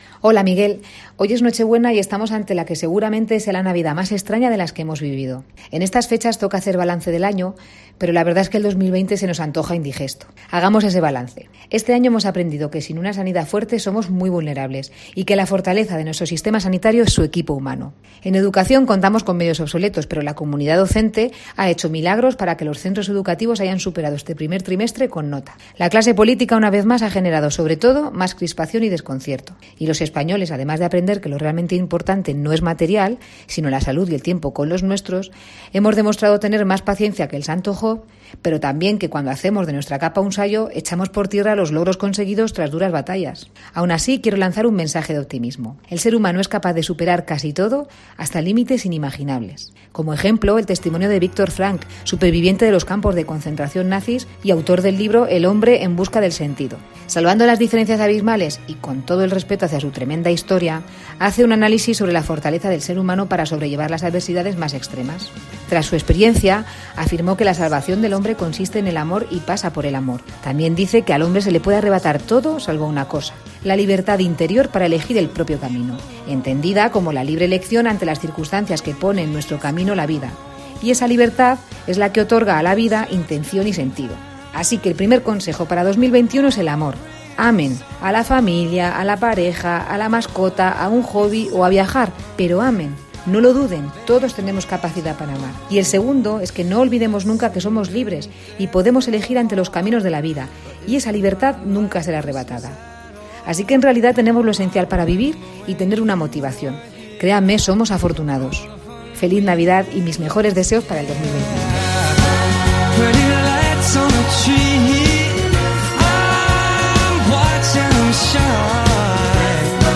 en tono de felicitación navideña